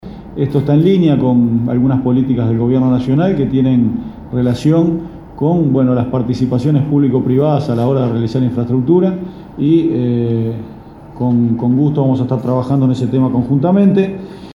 García dijo en conferencia de prensa que se trataron varios temas respecto a las decisiones que se deben tomar sobre los recursos que se le va a asignar a los gobiernos departamentales y la distribución, que la define el Congreso de Intendentes.